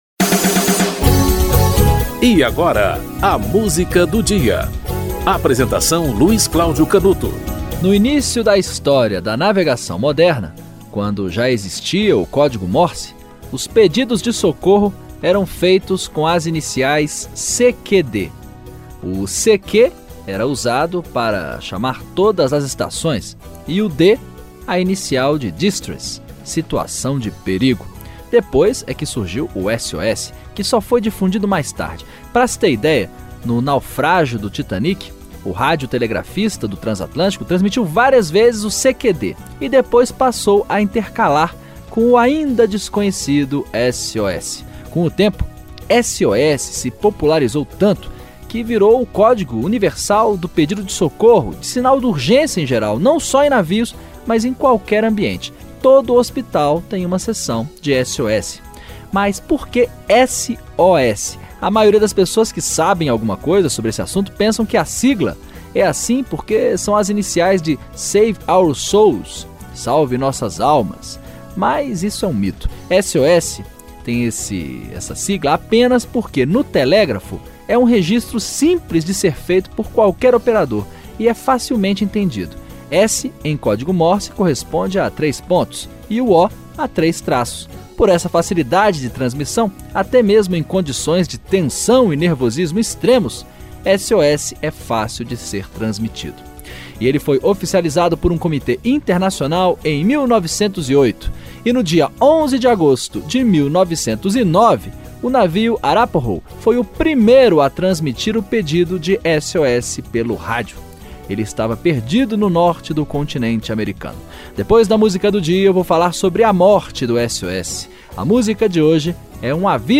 Lulu Santos - Aviso Aos Navegantes (Lulu Santos)